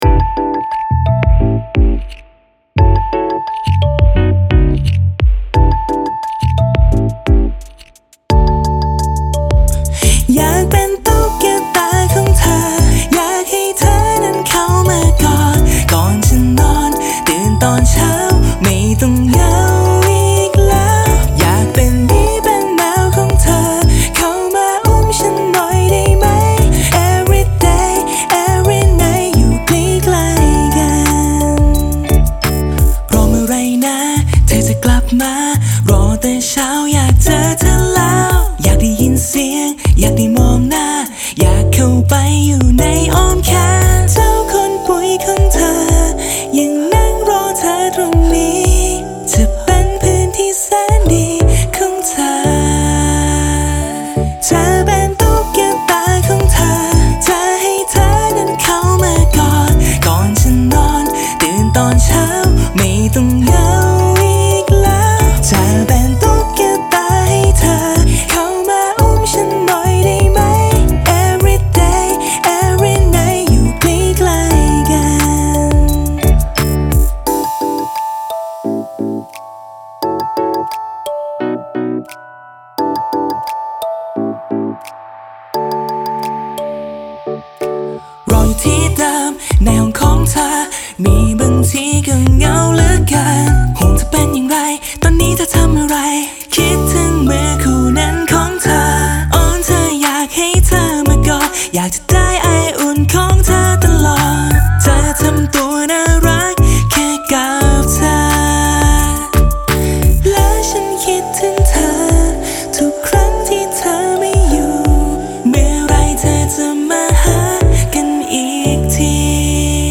3. เพลงความยาวมาตรฐาน 3 – 4 นาทีขึ้นไป
• โดยเพลงรูปแบบนี้ จะประกอบ “ท่อนเพลง” ตามมาตรฐาน เช่น